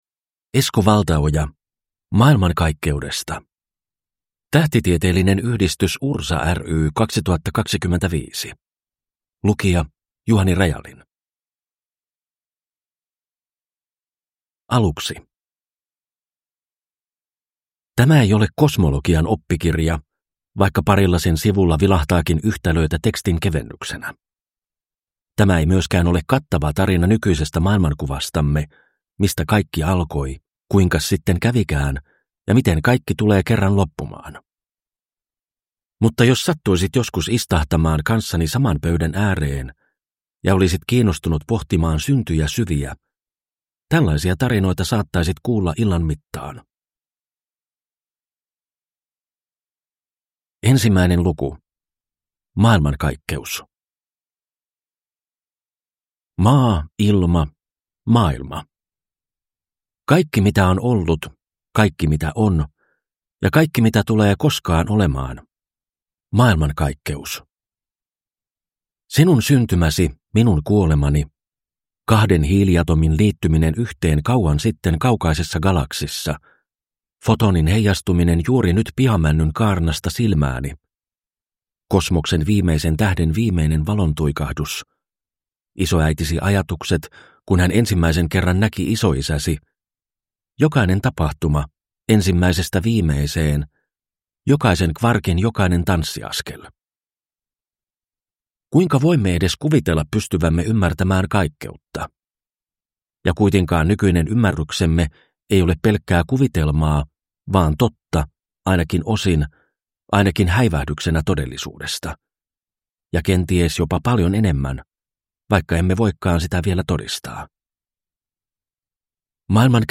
Maailmankaikkeudesta – Ljudbok